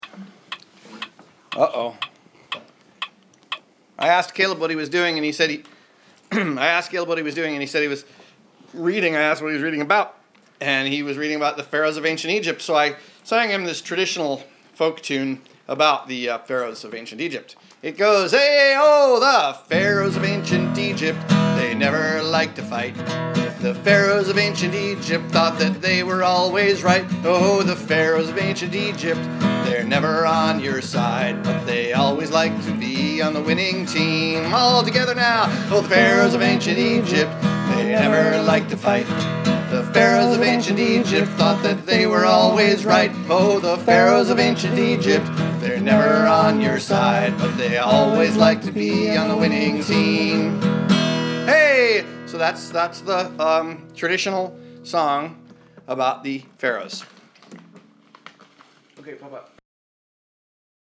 Recorded on the iPhone.